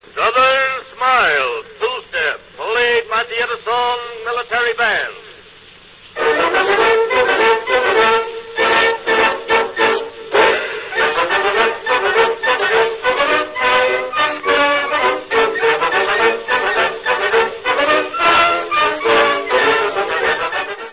Many two-minute cylinder recordings were self-announcing.
Listen to a self-announcement –